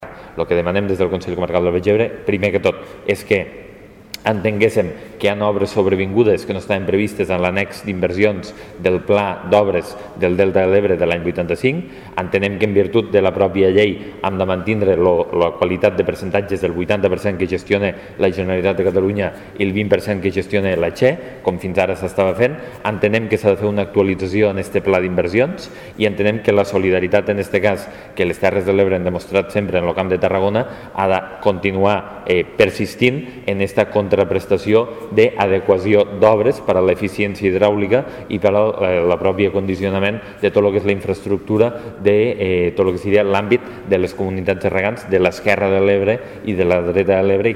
(tall de veu) Lluís Soler detalla la proposta de Presidència sobre els canvis de percentatge